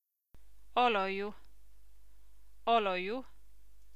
olju.mp3